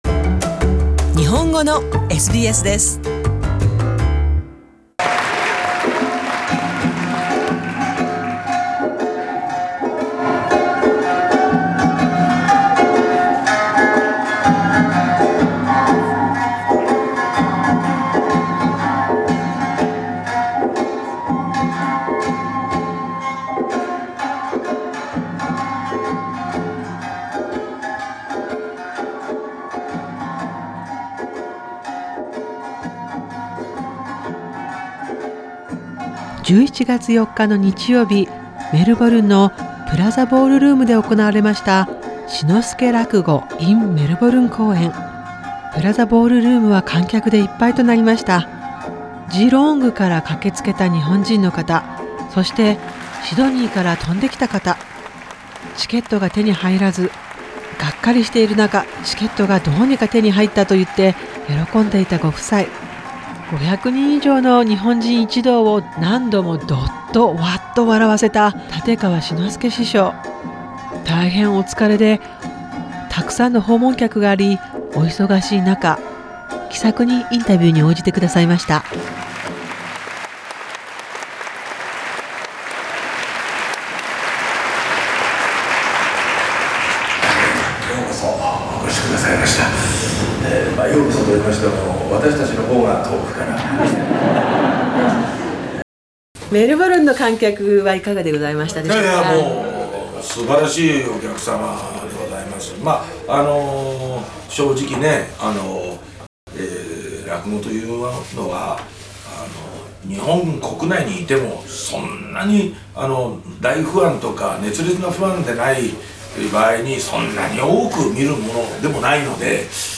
Over 500 Japanese speaking audience members laughed, giggled, roared with tears.